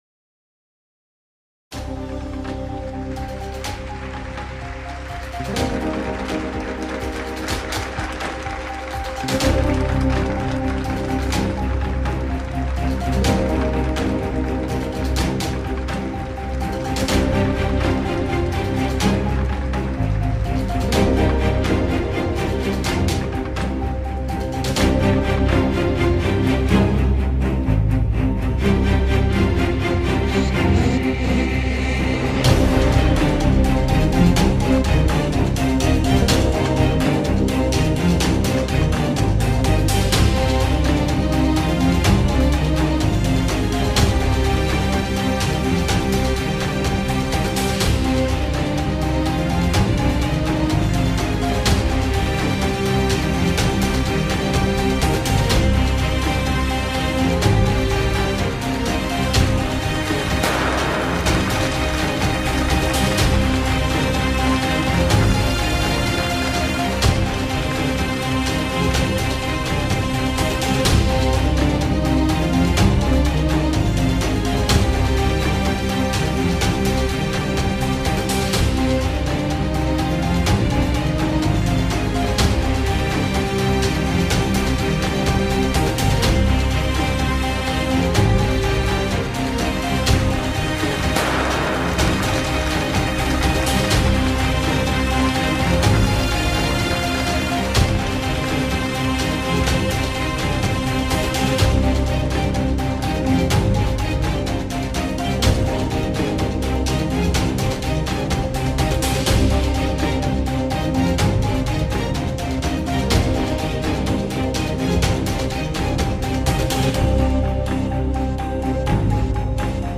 duygusal heyecan gerilim fon müziği.